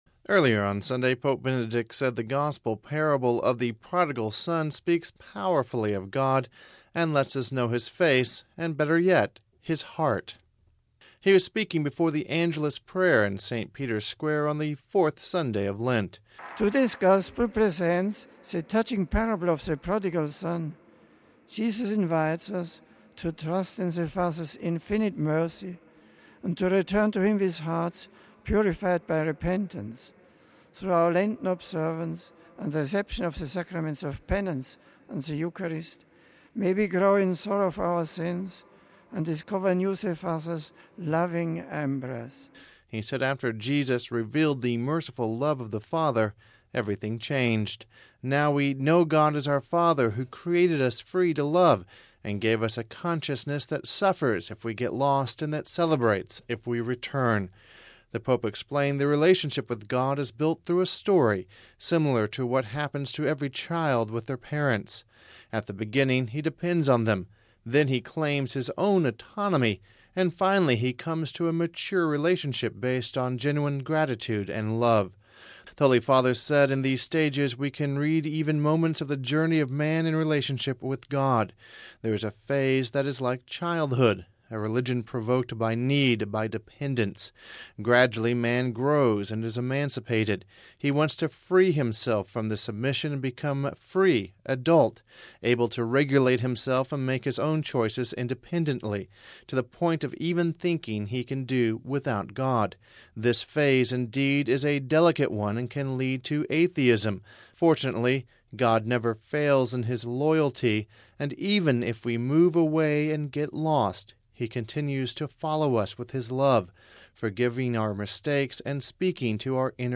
Home Archivio 2010-03-14 16:02:20 Pope Benedict XVI Speaks About Prodigal Son (14 Mar 10 - RV) Pope Benedict XVI spoke about the parable of the Prodigal Son during his Sunday Angelus. We have this report...